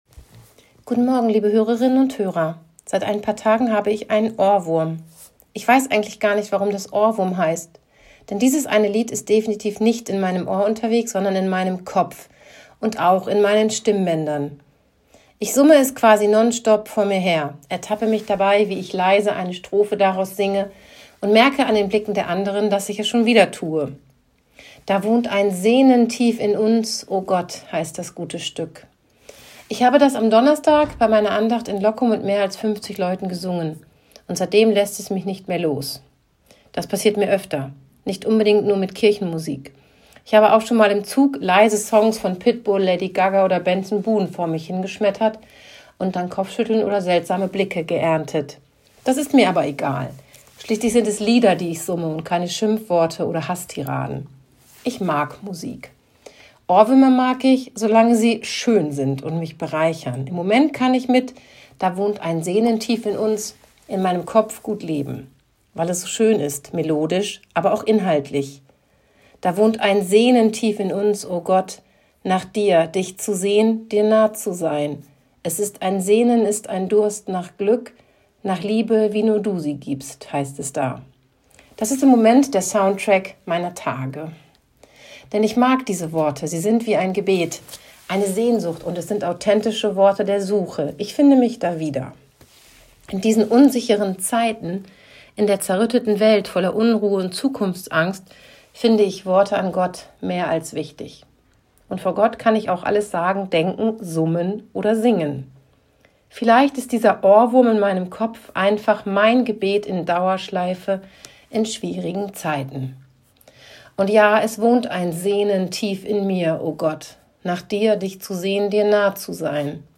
Radioandacht vom 24. März